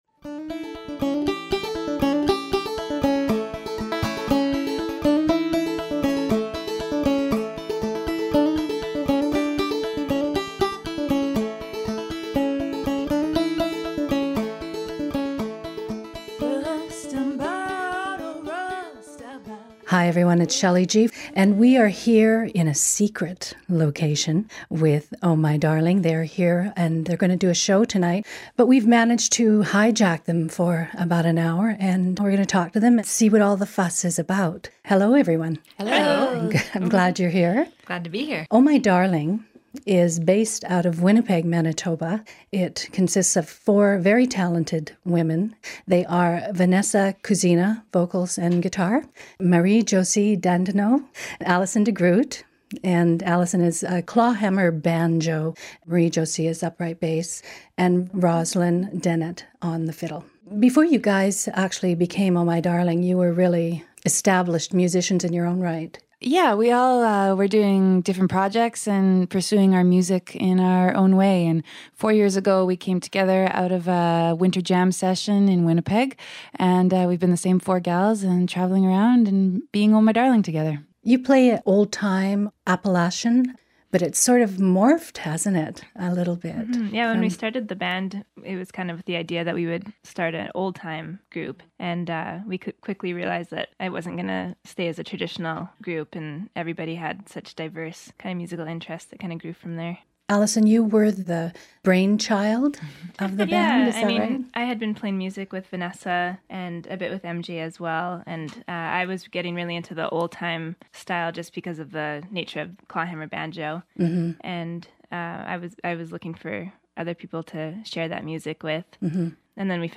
banjo/vocals
fiddle/vocals
guitar/fiddlesticks/vocals
upright bass/vocals
Interview and Performance